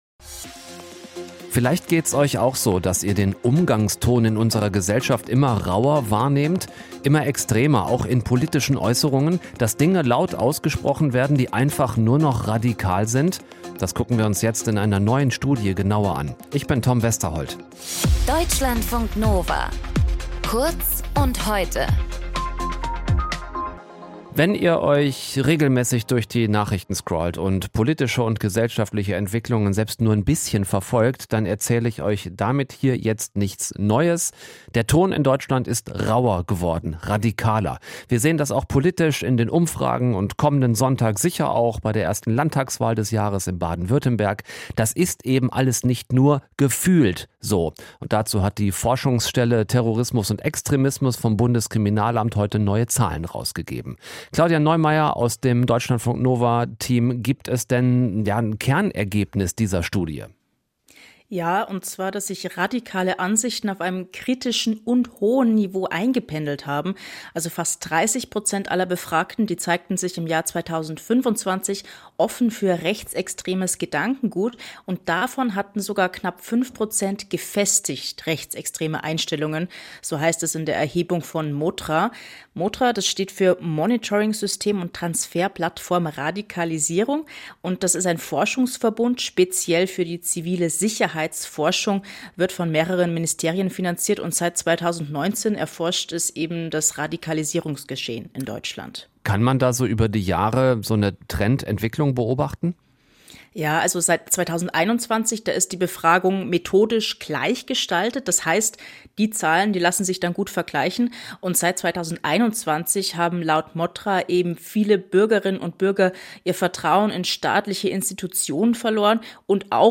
Moderation:
Gesprächspartnerin: